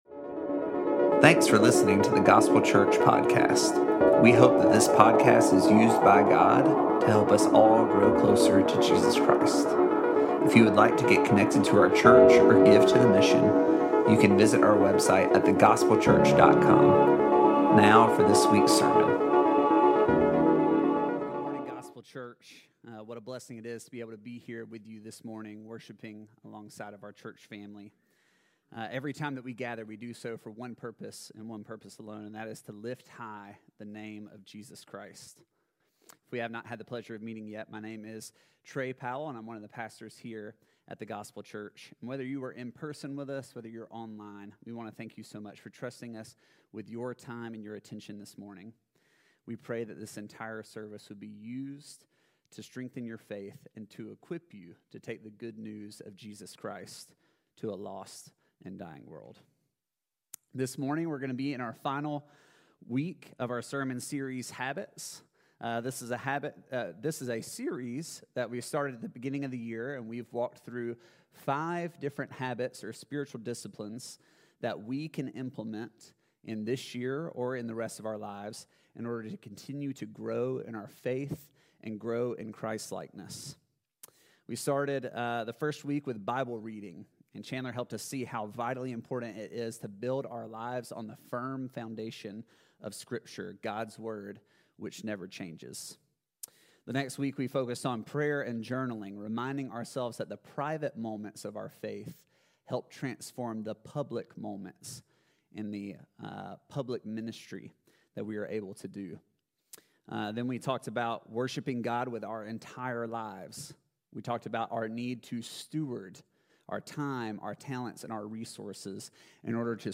preaches on the dynamics and virtue of biblical community.